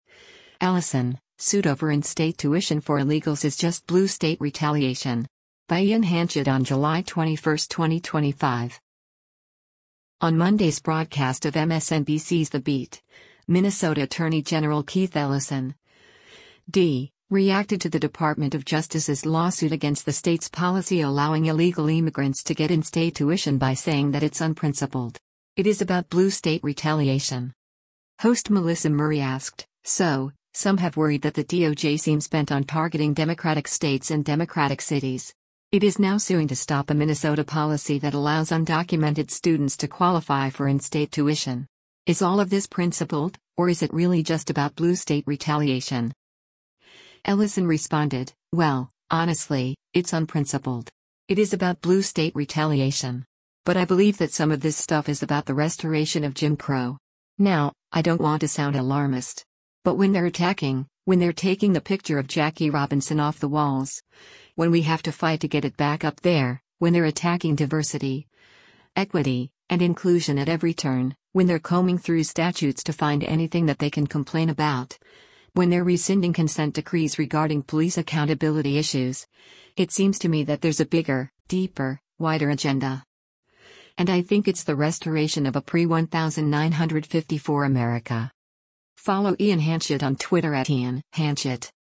On Monday’s broadcast of MSNBC’s “The Beat,” Minnesota Attorney General Keith Ellison (D) reacted to the Department of Justice’s lawsuit against the state’s policy allowing illegal immigrants to get in-state tuition by saying that “it’s unprincipled. It is about blue state retaliation.”